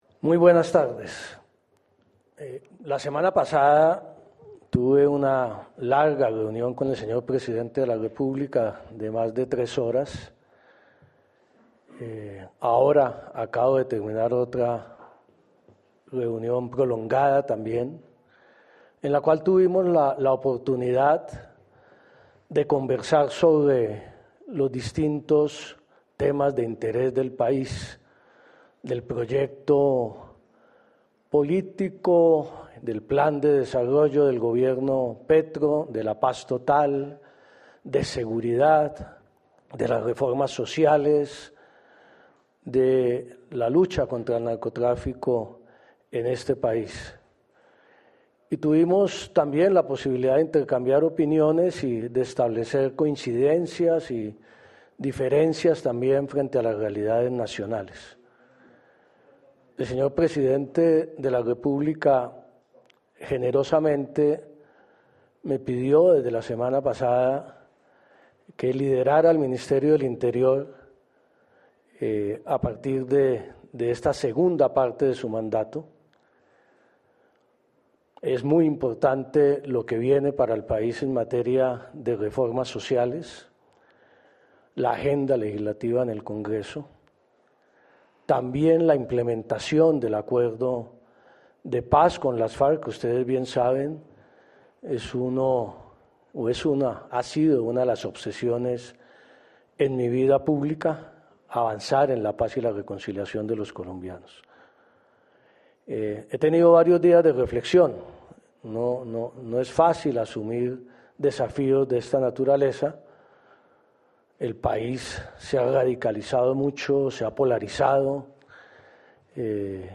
El presidente Gustavo Petro designó a Juan Fernando Cristo como nuevo ministro del Interior, quien en una primera declaración tras aceptar el cargo dijo que serán cuatro sus objetivos fundamentales.